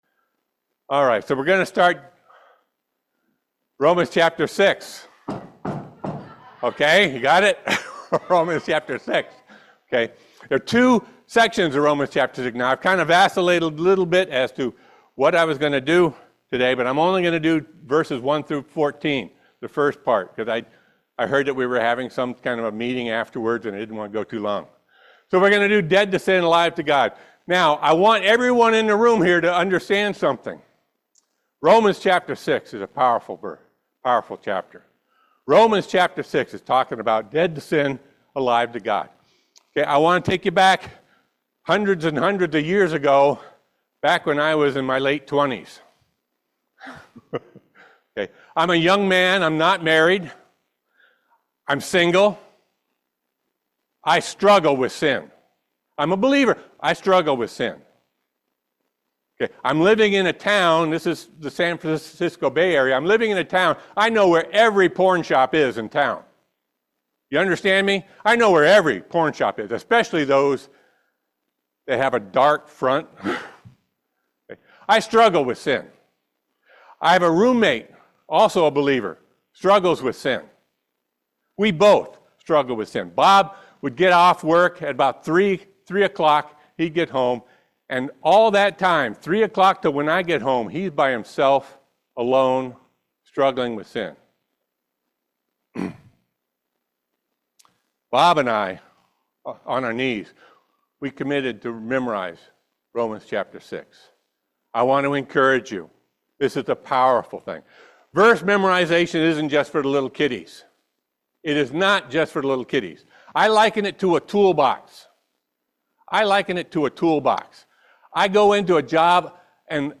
Romans 6:1-14 Service Type: Family Bible Hour The Lord Jesus Christ died for sin so the believer can no longer be a slave to sin.